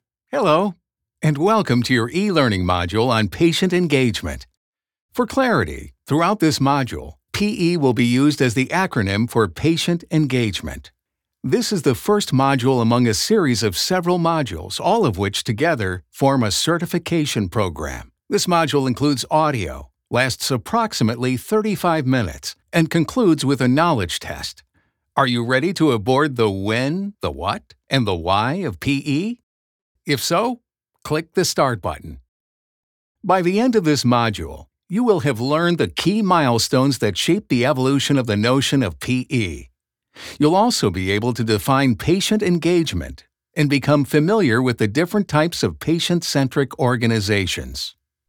Englisch (Amerikanisch)
Tief, Natürlich, Unverwechselbar, Freundlich, Warm
Erklärvideo